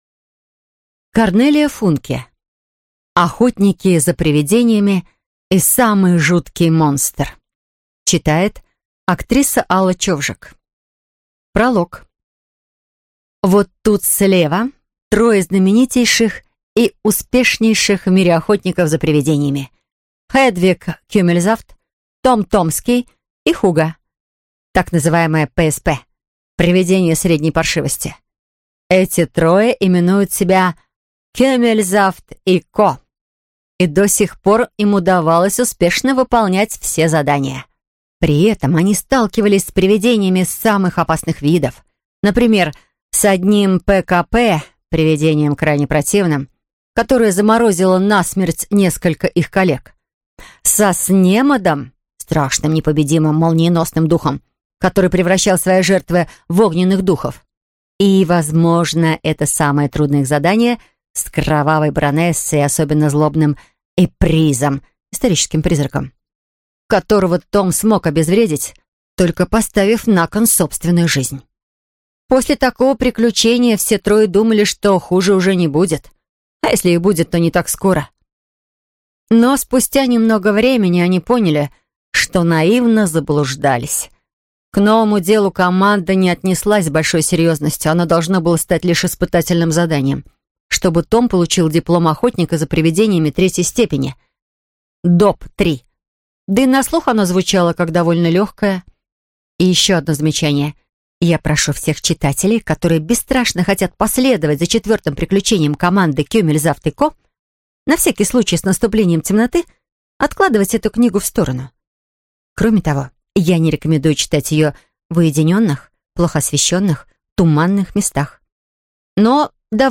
Аудиокнига Охотники за привидениями и самый жуткий монстр | Библиотека аудиокниг